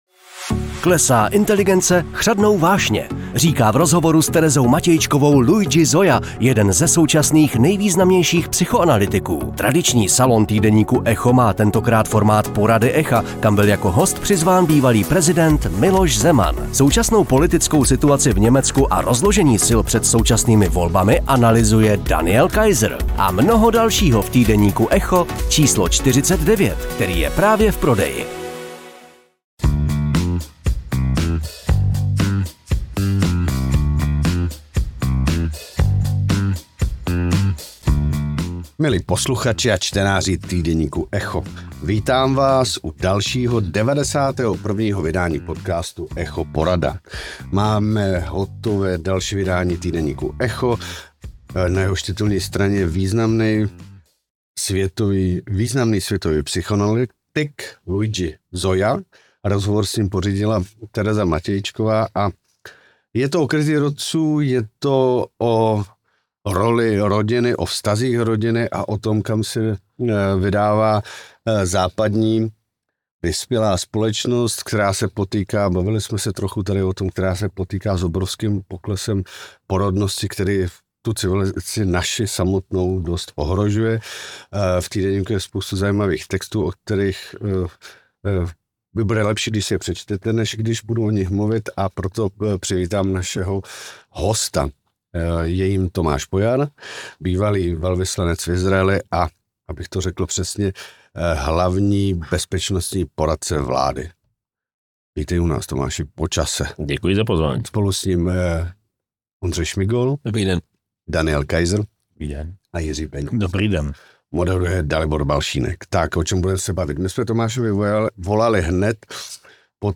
Co bude prezidentství Donalda Trumpa znamenat pro USA a co pro Evropu? A proč nejde Green Deal vetovat? Odpovídá host Echo Porady a hlavní bezpečnostní poradce vlády Tomáš Pojar.